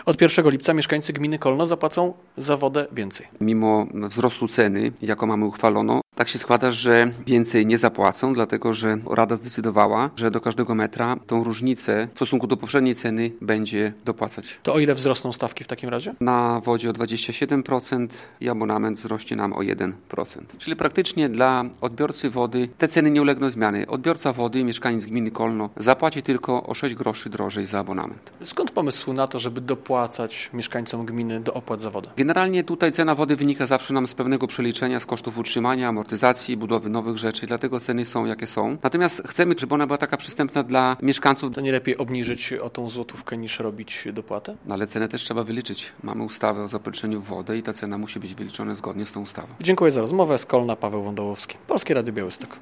Z wójtem gminy Kolno Tadeuszem Klamą rozmawia